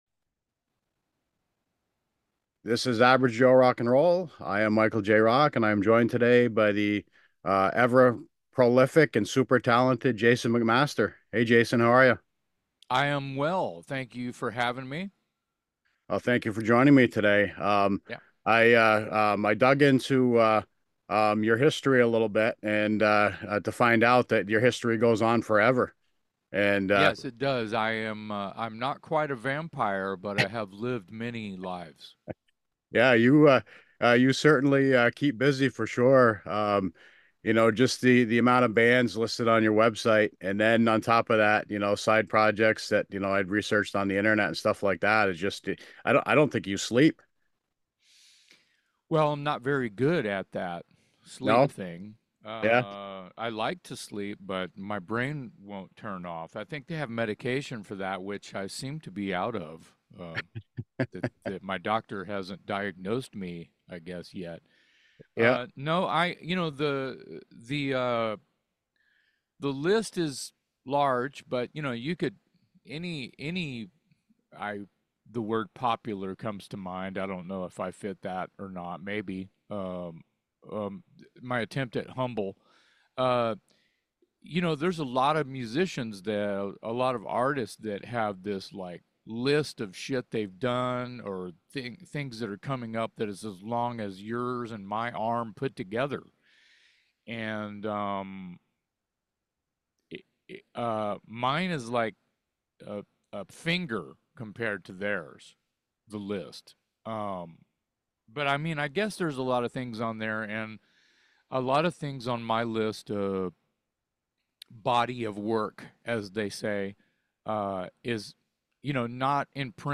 He is just as entertaining being interviewed as he is on stage!